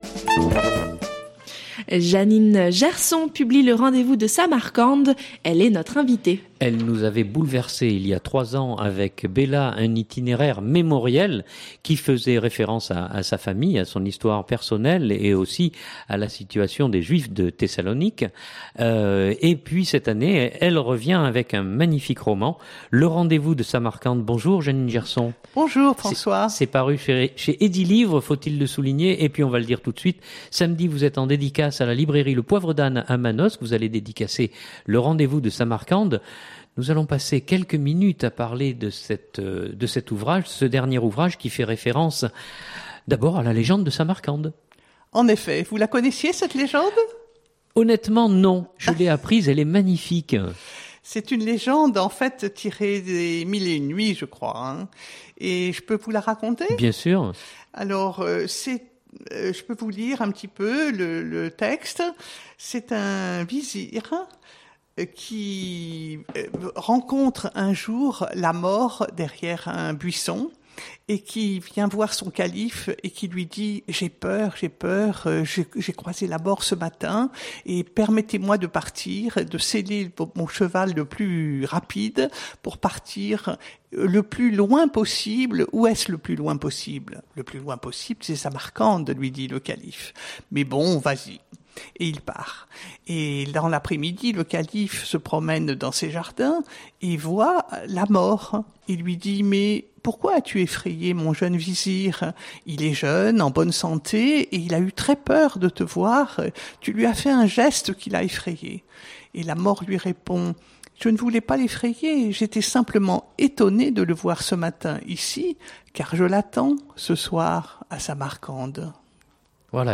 Sur le plateau de Fréquence Mistral, nous recevons